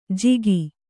♪ jigi